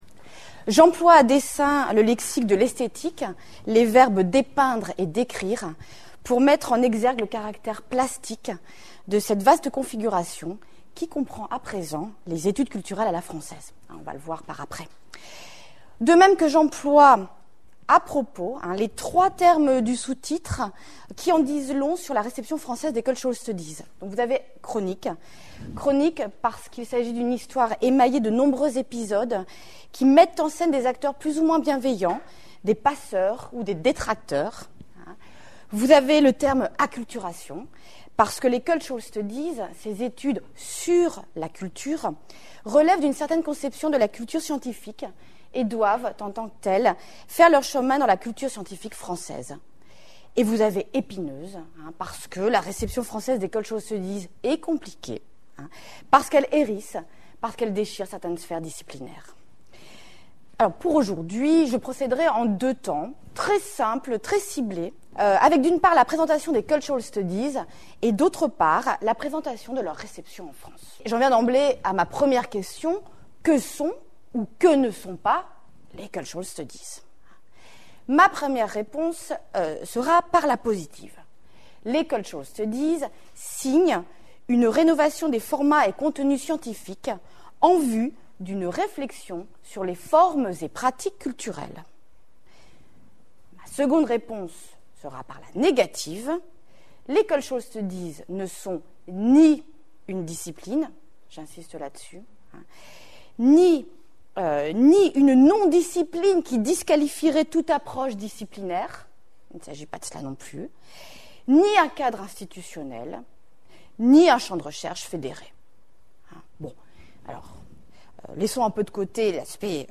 Cet exposé porte sur les Cultural Studies, un vaste champ d'études sur la culture et large ensemble de démarches scientifiques interdisciplinaires, qui pâtissent encore en France d'une certaine défaveur, ou bien d'une méconnaissance qui contrarie leur réception.